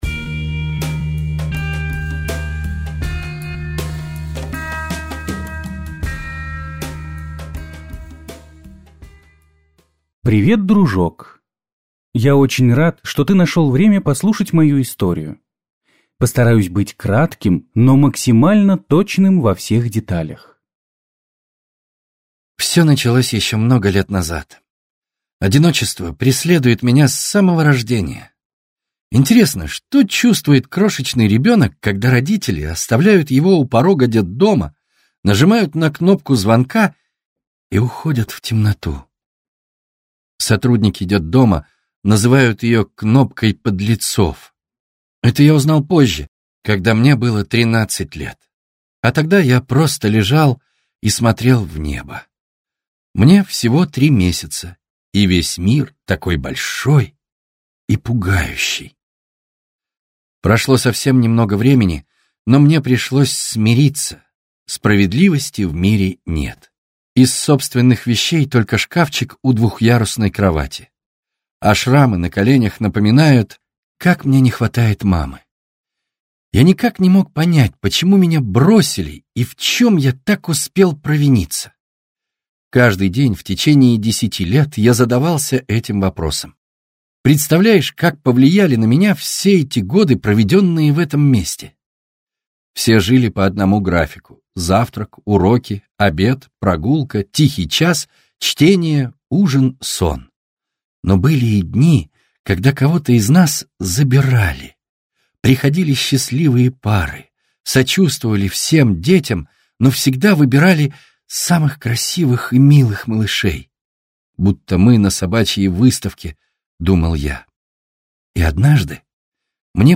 Аудиокнига Мятная сказка | Библиотека аудиокниг